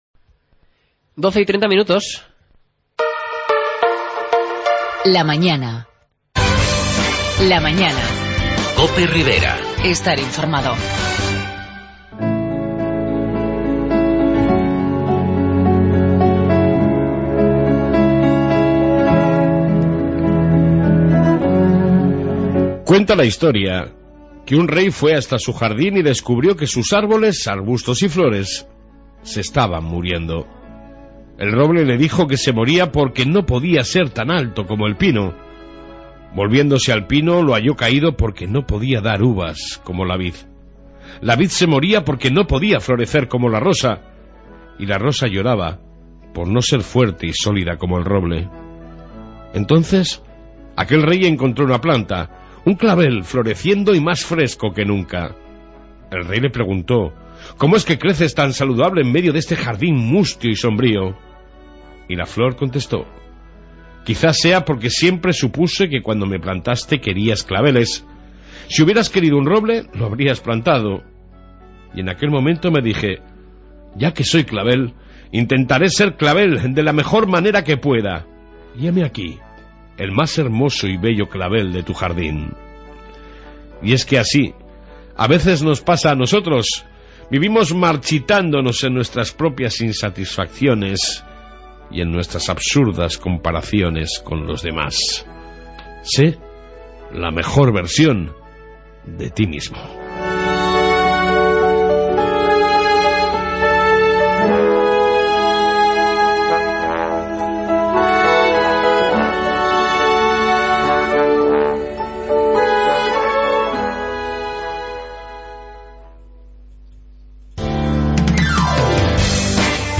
AUDIO: En esta 1ª parte Reflexión diaria y amplia entrevista con AIRE (Asociación Impulso Ribera Emprendedora)